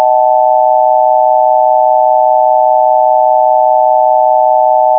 3tonecomplex125Hz.wav